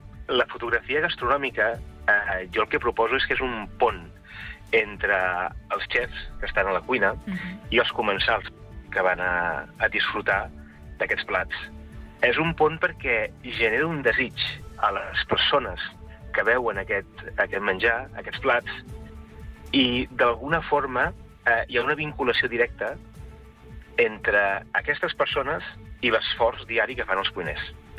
Entrevistes SupermatíSupermatí
En una entrevista concedida al Supermatí